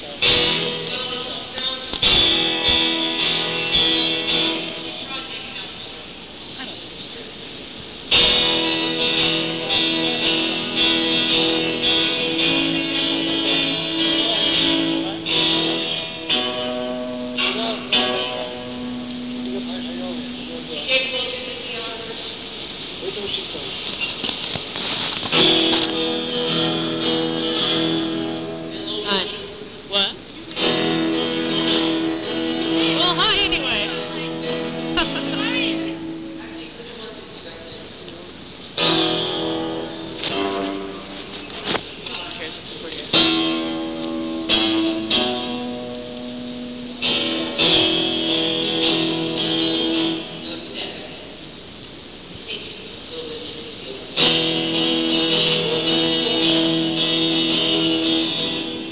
(band show)
(soundcheck)